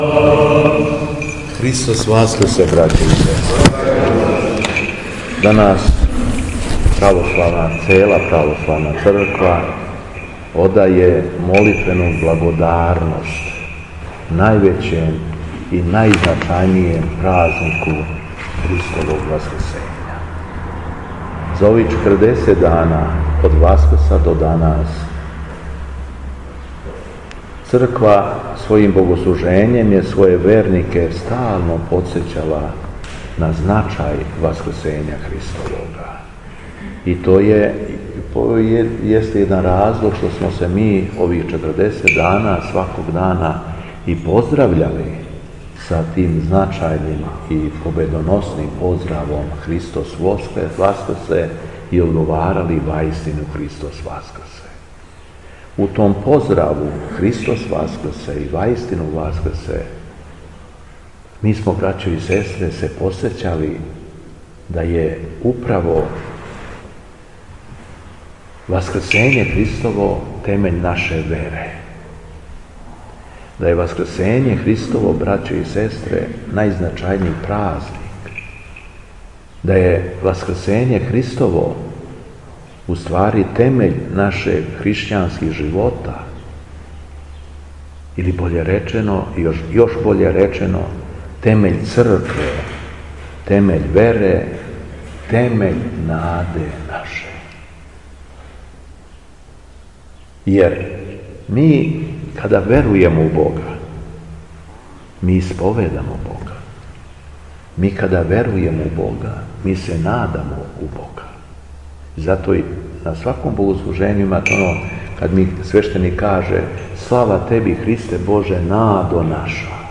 На Оданије Пасхе, 1. јуна 2022. године, Његово Преосвештенство Епископ шумадијски Господин Јован служио је свету архијерејску литургију у храму светог...
Беседа Његовог Преосвештенства Епископа шумадијског г. Јована